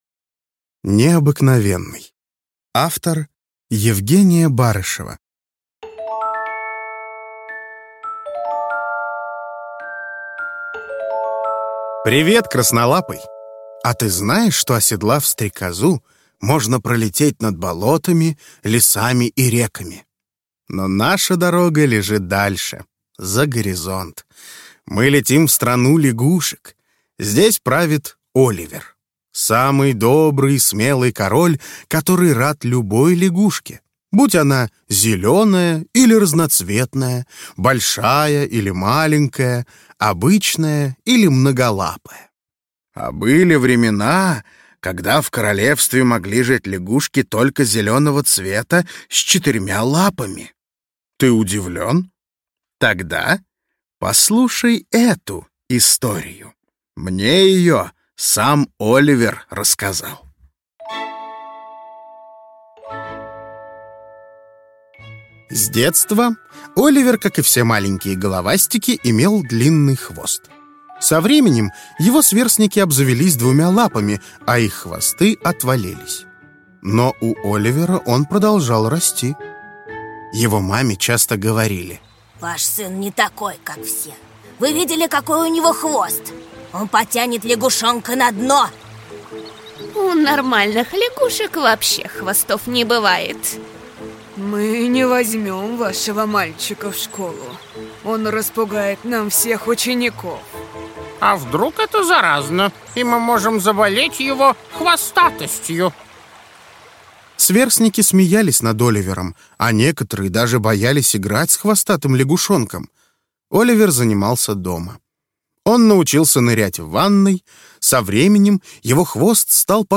Аудиокнига Необыкновенный | Библиотека аудиокниг